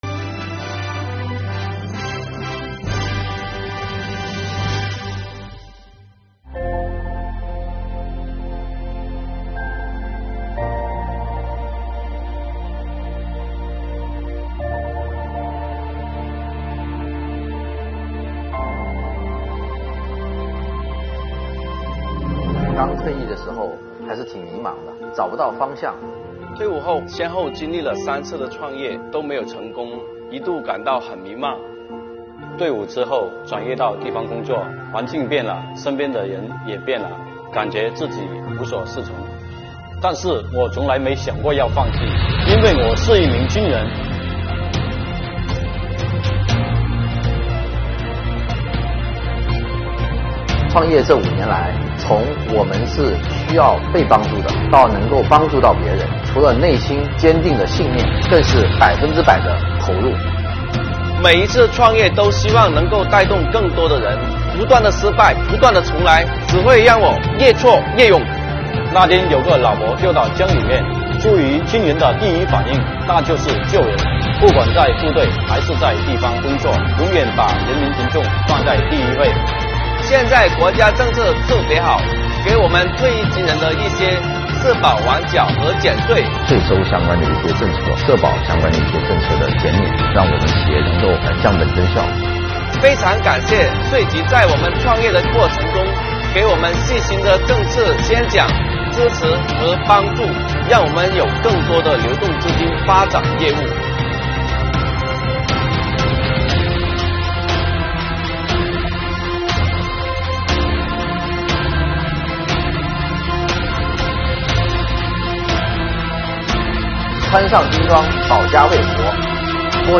短片以自述手法，把刚退役后的迷茫、以军人品质战胜困难、在创业就业新路上实现自我的各个阶段流畅串联，适时体现助力退役军人创业就业的各项优惠政策。